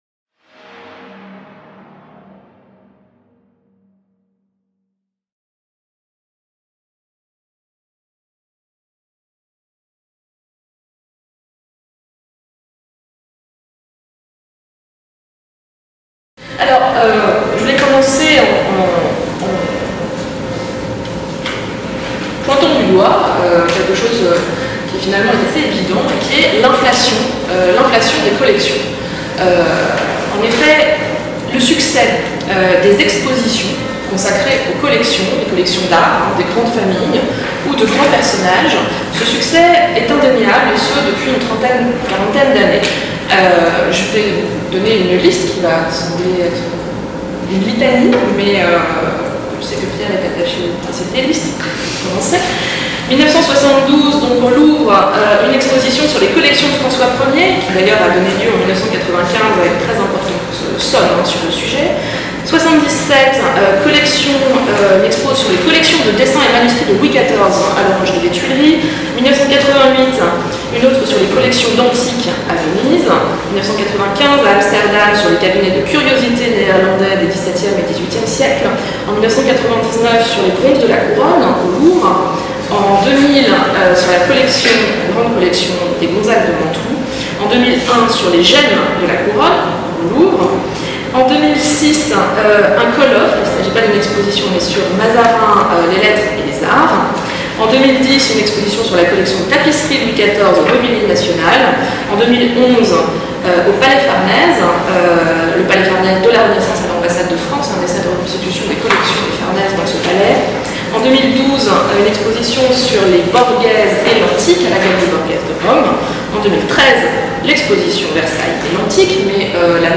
Première séance du séminaire "Collections" 2014-2015.